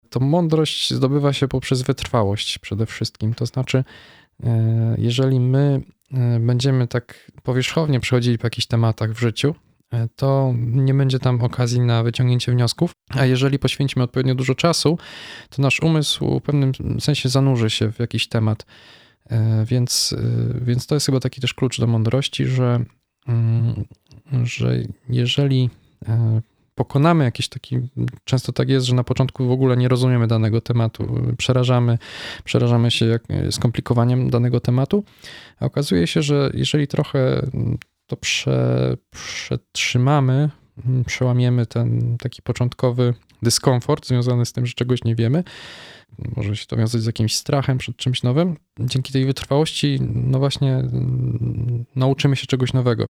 W tej rozmowie spróbujemy przyjrzeć się inteligencji z perspektywy psychologii – od biologicznych podstaw, przez rodzaje inteligencji, aż po jej rozwijanie w ciągu życia. Zastanowimy się także, czym różni się inteligencja od mądrości i czy można się jej nauczyć.